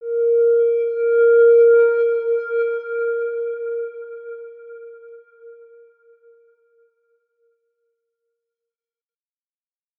X_Windwistle-A#3-ff.wav